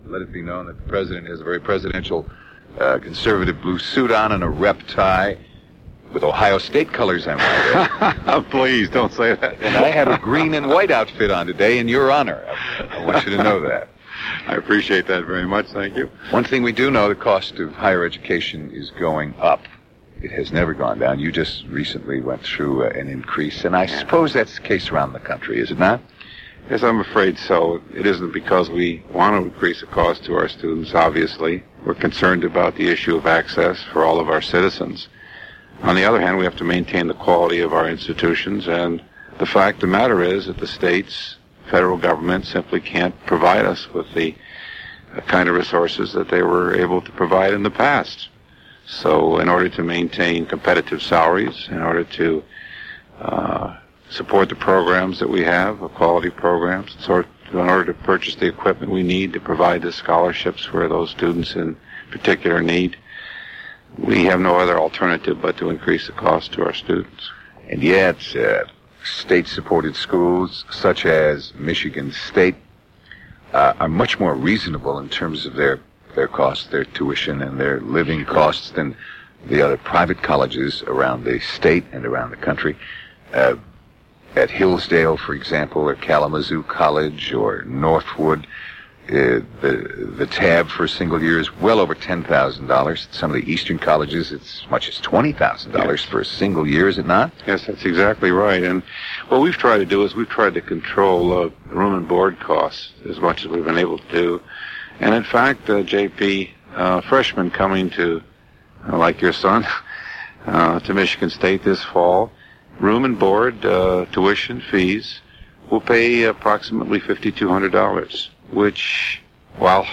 A digitized recording of an audio cassette.
The cassette tape went through noise reduction, was mixed and rendered, but limitations of the source tape are still present.
Original Format: Audio cassette tape